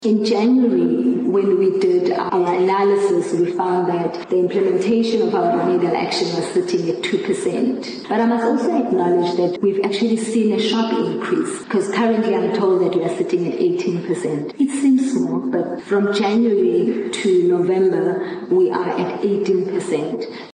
Gcaleka wat die Kaapstadse Persklub toegespreek het, het die uitdaging om so ŉ maatreël in te stel, uitgelig. Sy erken dat so ŉ poging ingewikkeld is en beklemtoon die behoefte aan streng meganismes om dié doel te bereik: